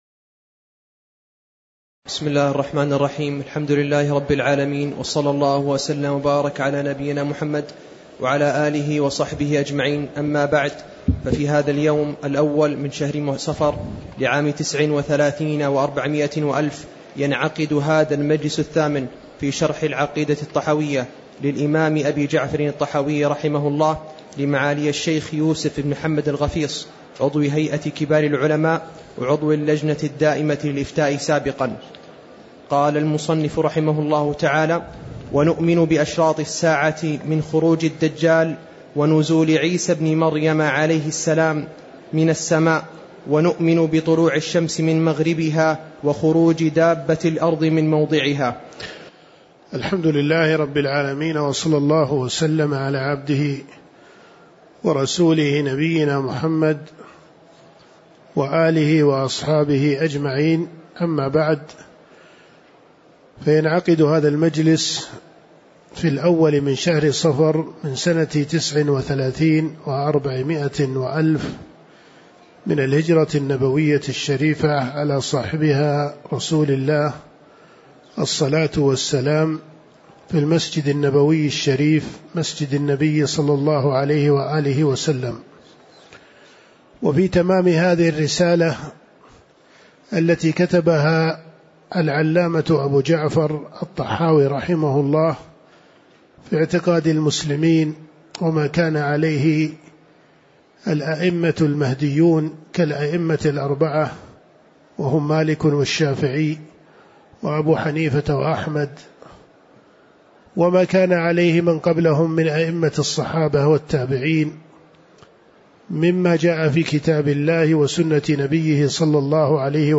أما بعد: فينعقد هذا المجلس في الأول من شهر صفر من سنة (1439) من الهجرة النبوية الشريفة، على صاحبها رسول الله الصلاة والسلام، في المسجد النبوي الشريف ؛ مسجد النبي  .